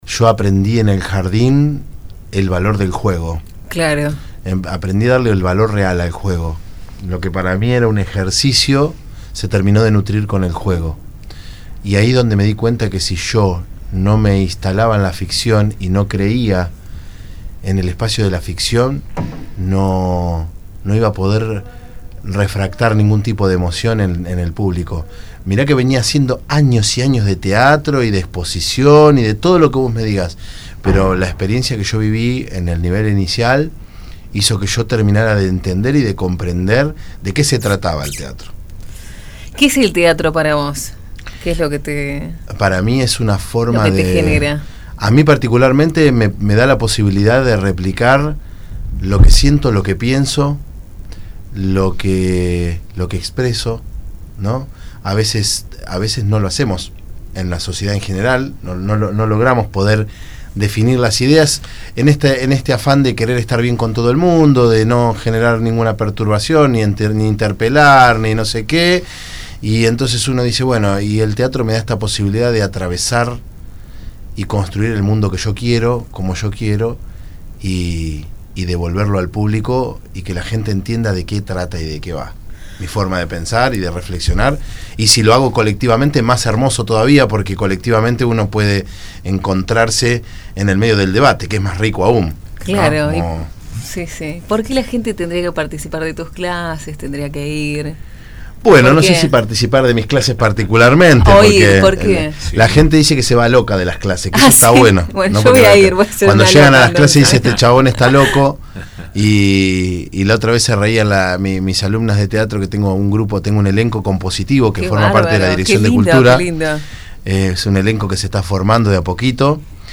En un extenso reportaje